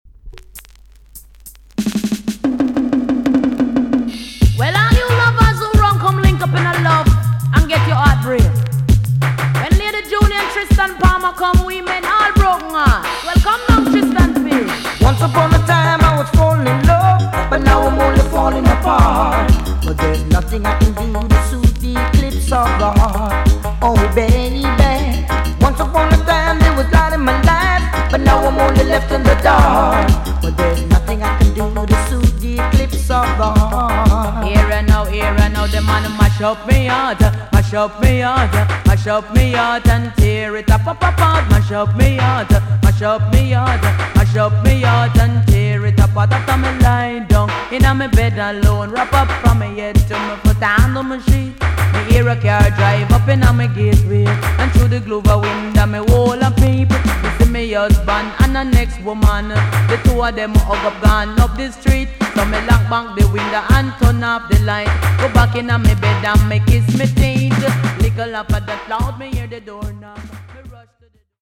TOP >80'S 90'S DANCEHALL
EX- 音はキレイです。